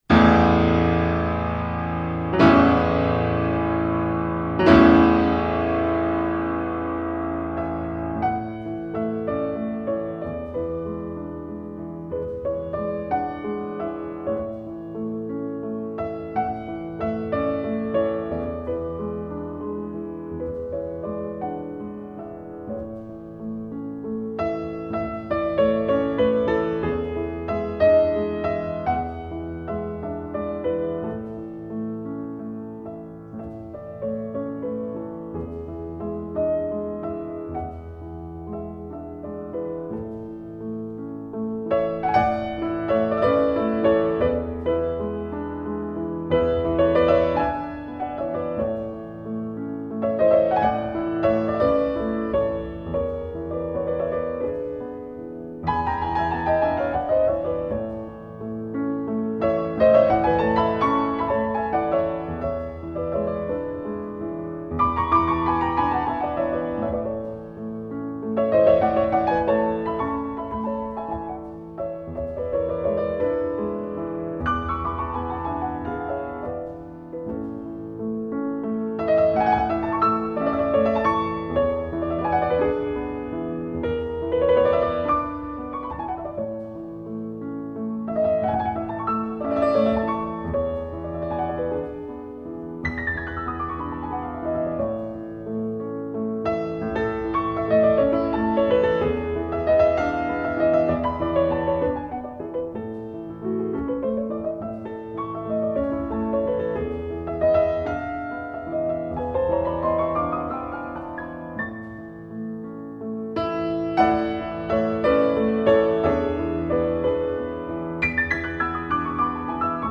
Incontro
Nel corso della puntata la sentiremo nell'esecuzione di tre diversi interpreti: Artur Rubinstein, Murray Perahia e Maurizio Pollini.